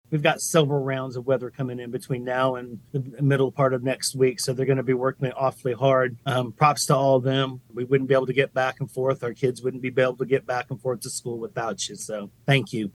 Riley County Commissioner John Ford took time to thank the county’s snow plow operators and emergency responders publicly during KMAN’s In Focus Tuesday.